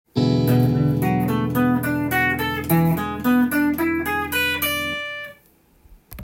７ｔｈ　ⅡーⅤ　コード例
E7(Bm7/E7)